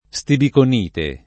[ S tibikon & te ]